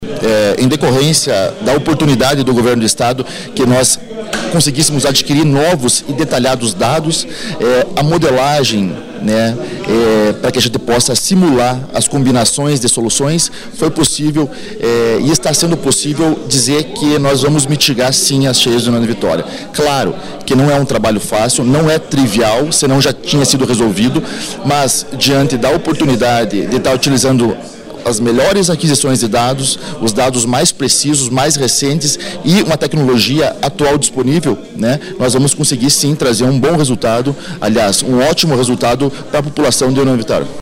Durante a coletiva, foi destacado que a geografia de União da Vitória — localizada em uma área de confluência e cercada por morros — a torna naturalmente mais suscetível a alagamentos severos.
07-engenheiro.mp3